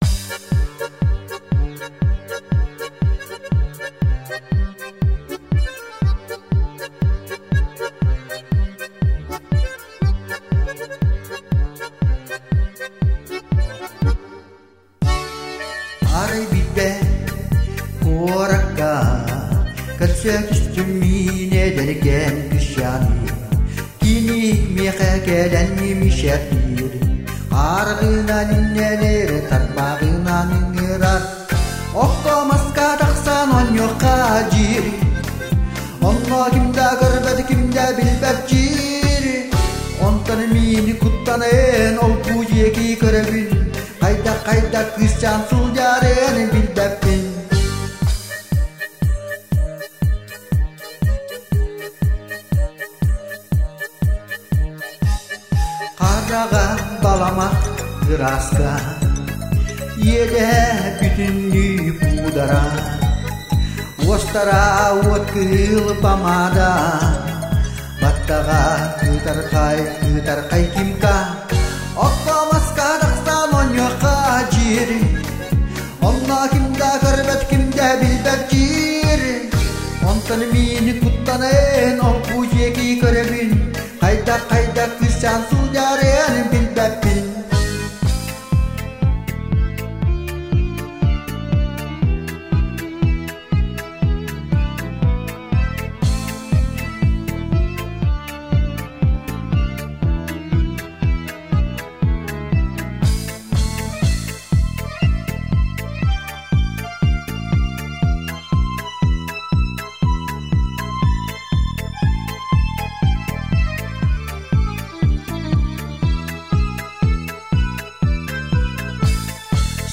Көрдөөх ырыа.